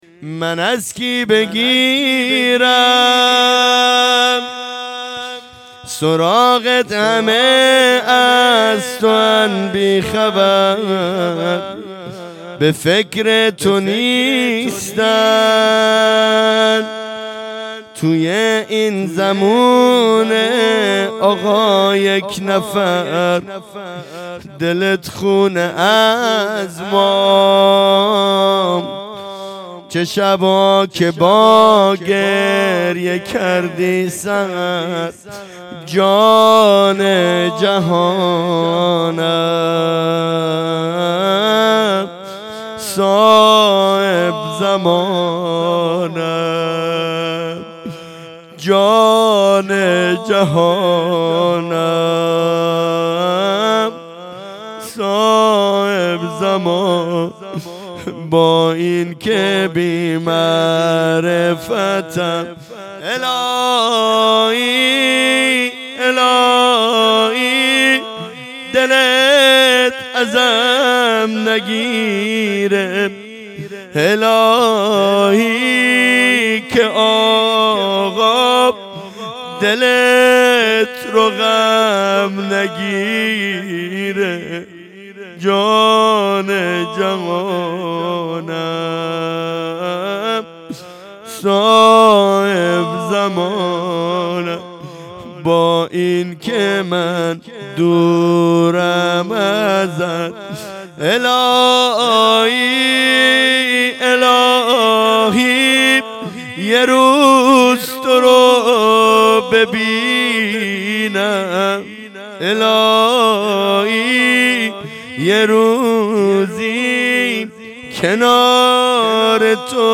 شهادت امام جواد علیه السلام 1404
زمزمه امام زمانی